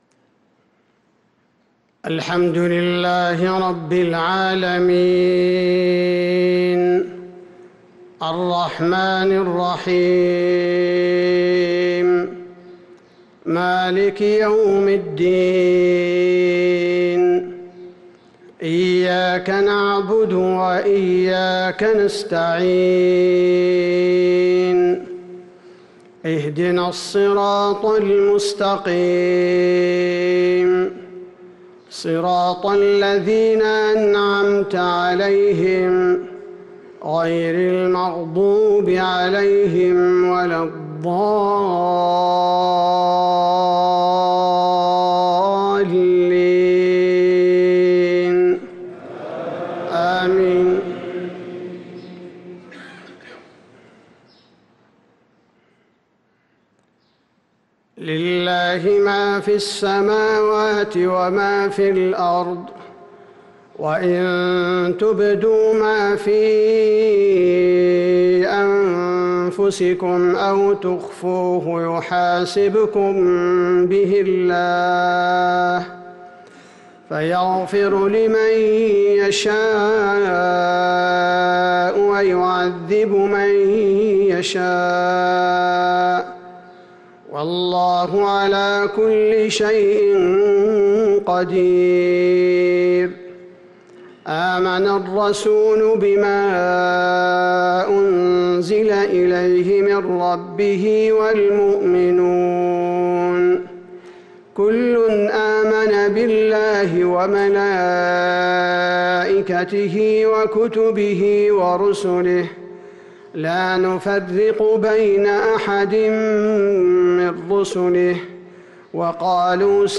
صلاة المغرب للقارئ عبدالباري الثبيتي 23 جمادي الآخر 1445 هـ
تِلَاوَات الْحَرَمَيْن .